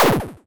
poly_shoot_laser04.wav